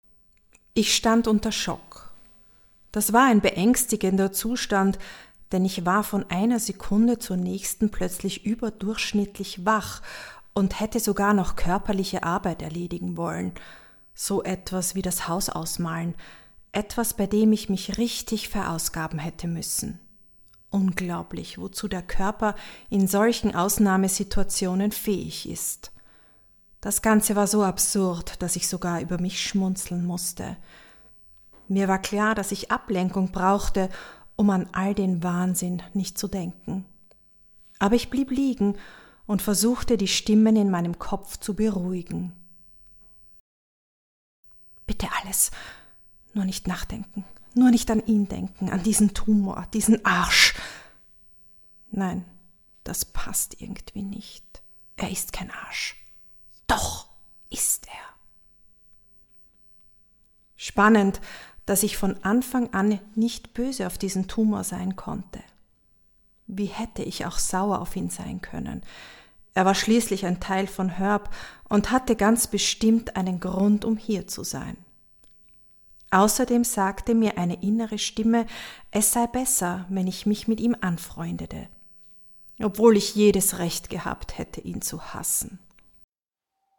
Hörbuch: Ich geh mit dir bis zum Regenbogen